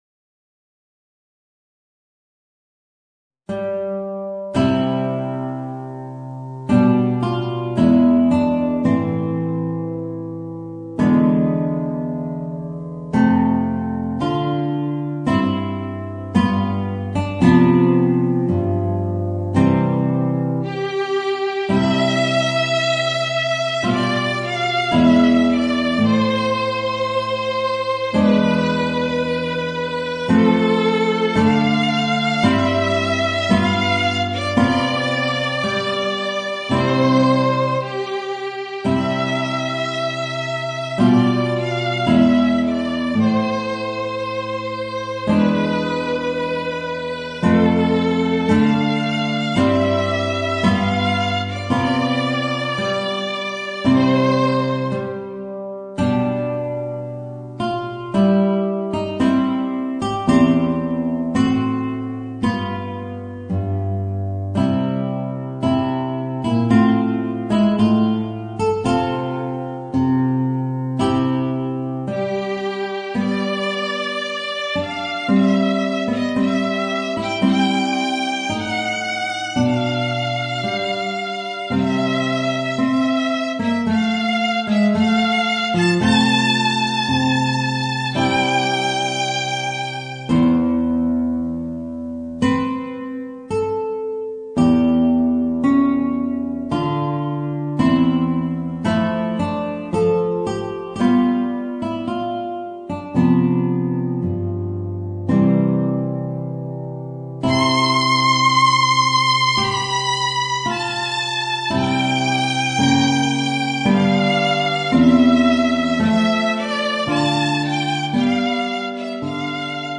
Voicing: Violin and Guitar